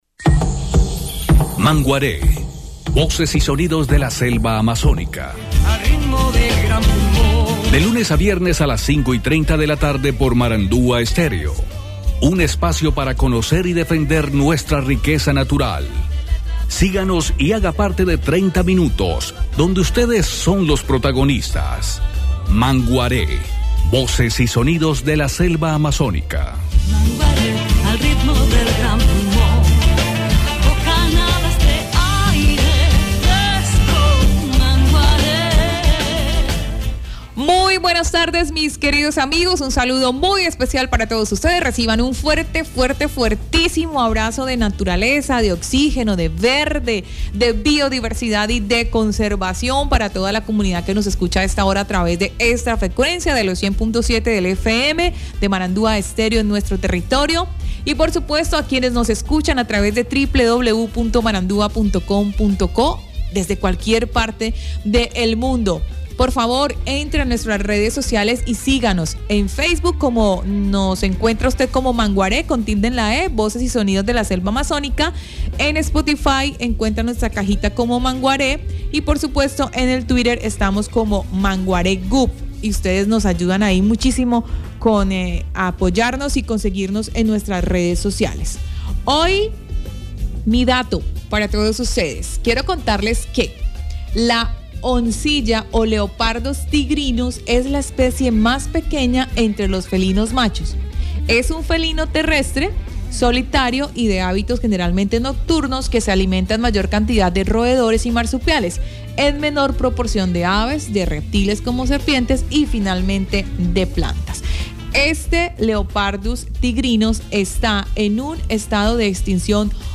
Ministerio de Medio Ambiente y Desarrollo Sostenible, cambia el enfoque de la estrategia de lucha contra la deforestación estará orientada a los derechos, la que, sin descuidar el control y la labor constitucional que el Estado debe ejercer, se hará sobre la base del Estado Social de Derecho. Susana Muhamad, ministra nos da los detalles.